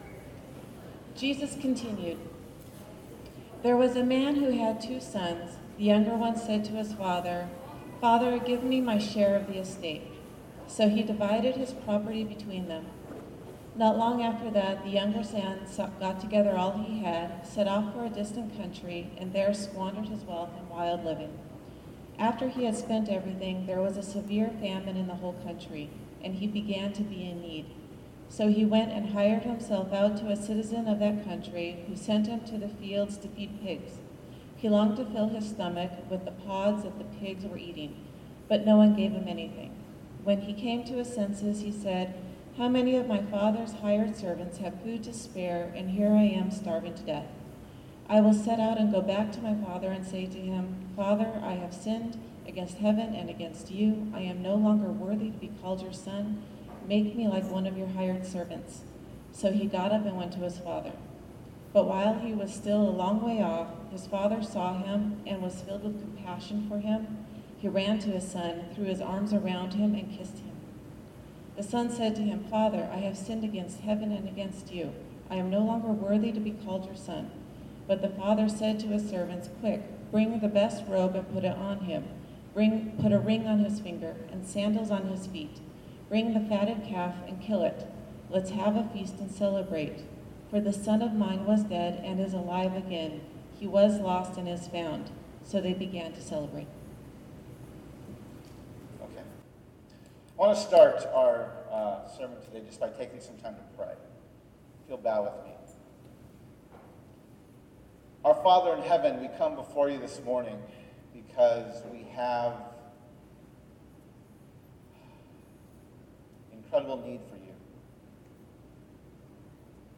Audio: Before Being Sent (Sermon)
On Sunday January 14, I had the privilege of preaching at Peace Fellowship Church in Washington DC. My passage was Luke 15:11-24, the parable of the Prodigal Son. In this sermon I present insight into this parable from the perspective of my Navajo culture.